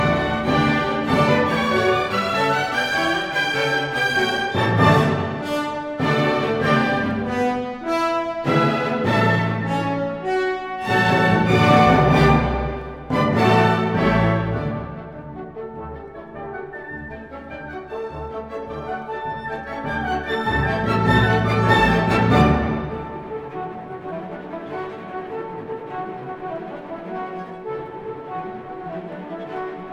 # Классика